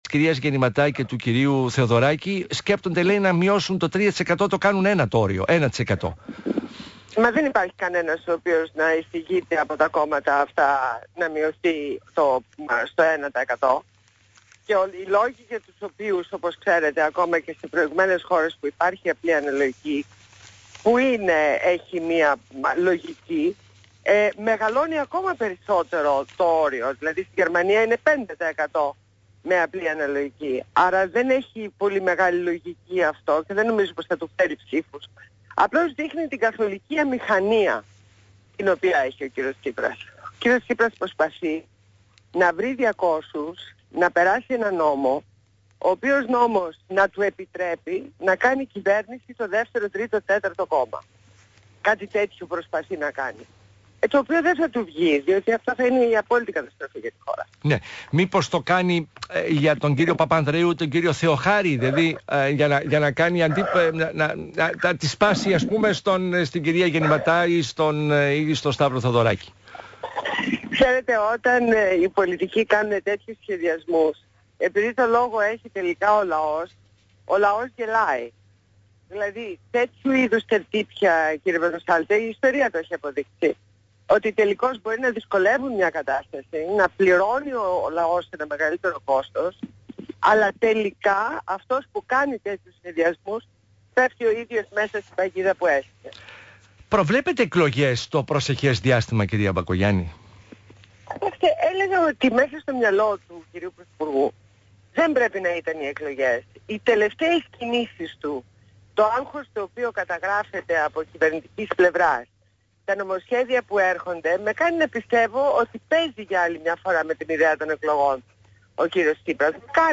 Συνέντευξη στο ραδιόφωνο του ΣΚΑΙ στο δημοσιογράφο Α. Πορτοσάλτε.